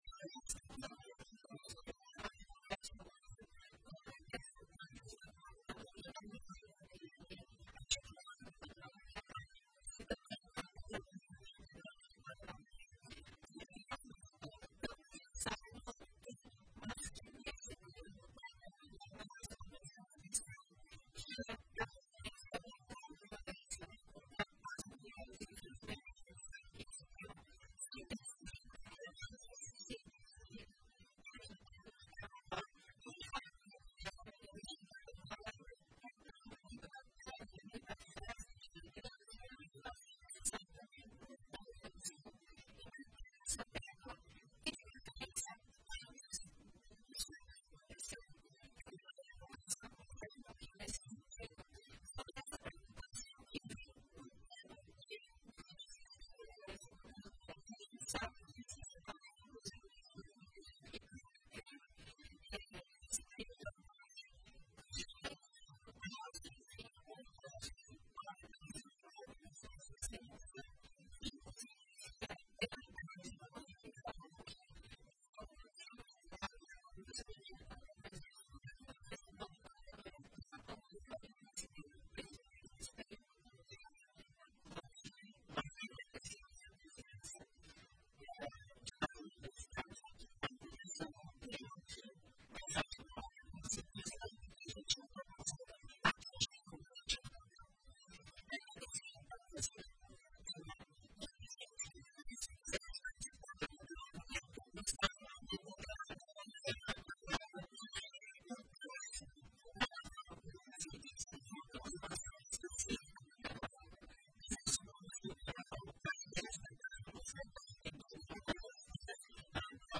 Em entrevista à RPI, Coordenadoria da Mulher reforça combate à importunação e assédio durante Carnaval